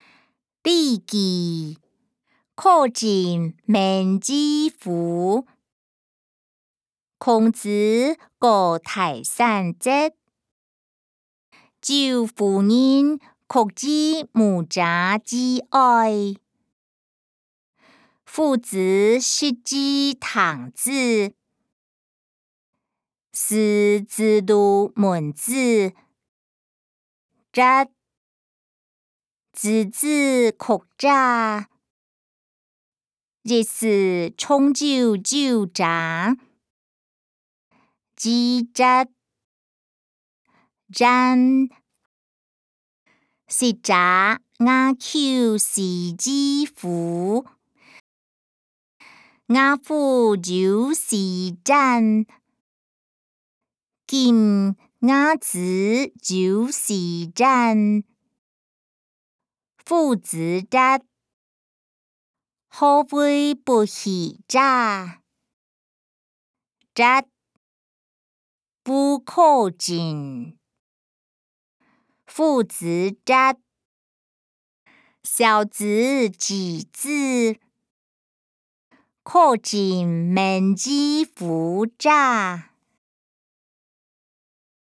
經學、論孟-苛政猛於虎音檔(海陸腔)